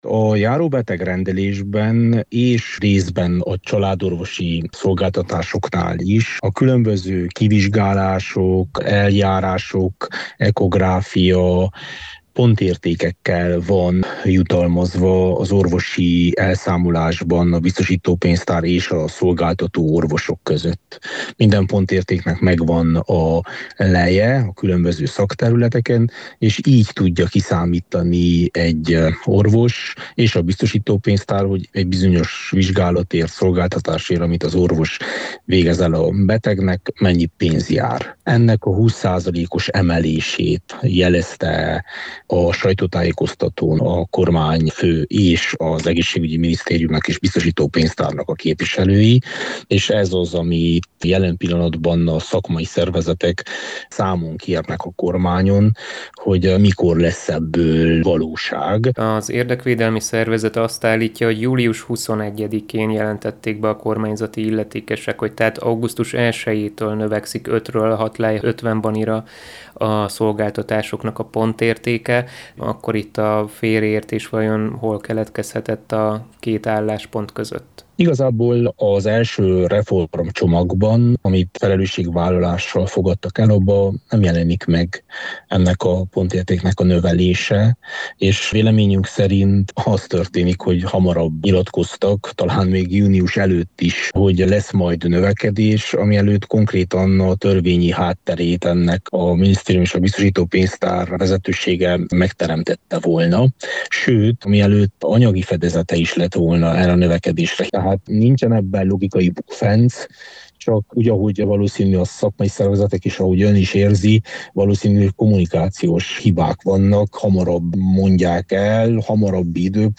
A pontértékek növeléséről az RMDSZ egészségügyi szakpolitikusát, dr. Vass Leventét kérdezte